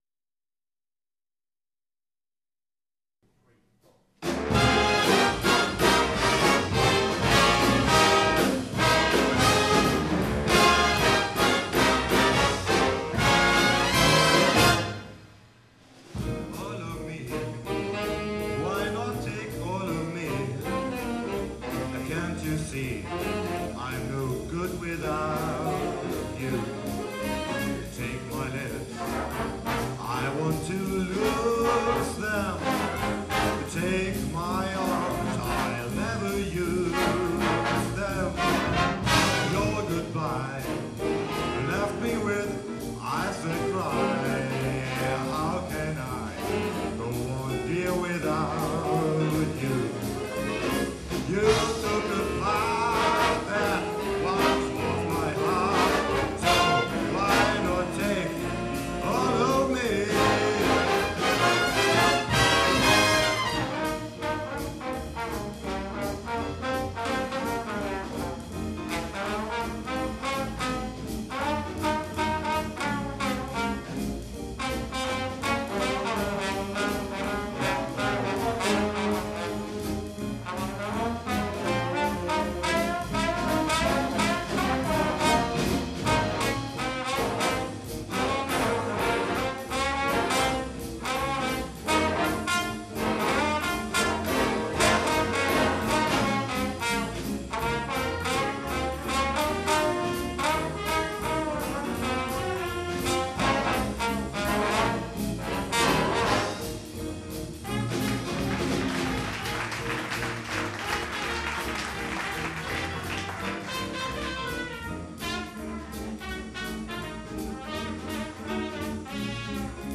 · Genre (Stil): Big Band